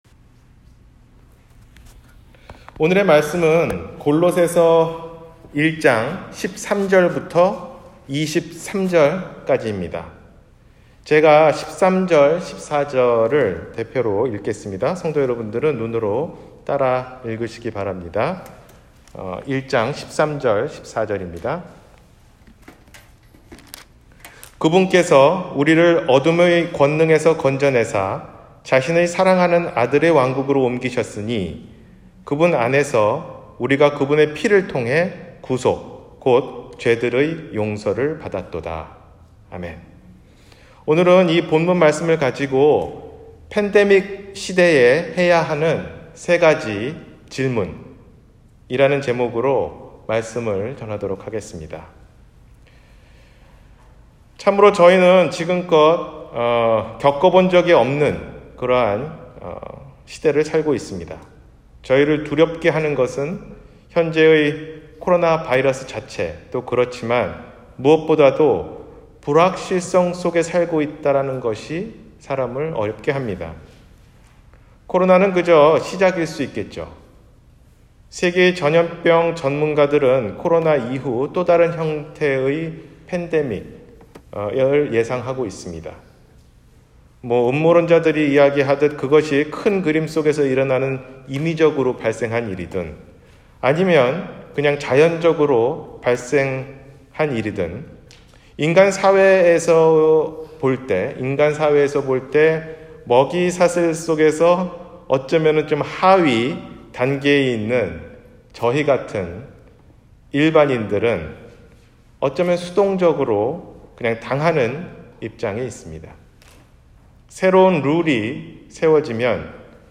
팬데믹 시대에 해야할 세가지 질문 – 주일설교